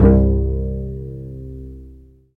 Index of /90_sSampleCDs/Roland - String Master Series/STR_Vcs Marc-Piz/STR_Vcs Pz.4 Oct
STR CLLO P00.wav